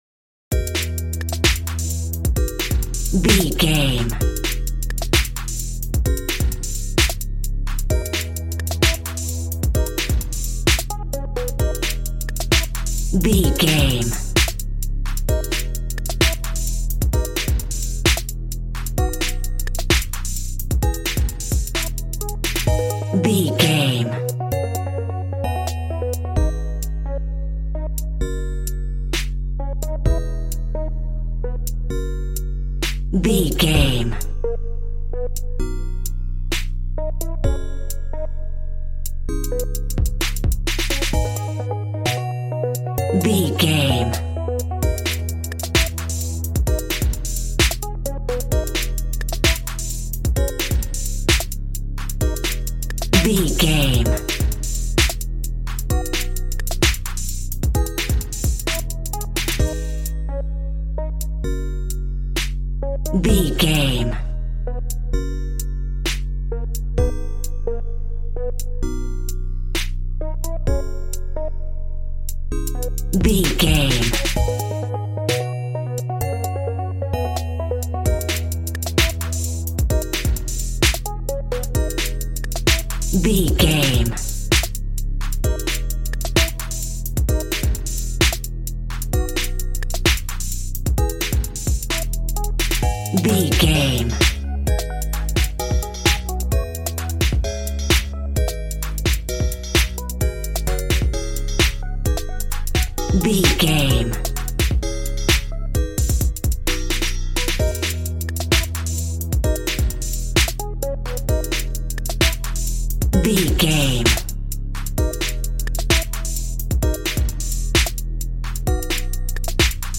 Aeolian/Minor
A♭
calm
smooth
synthesiser
piano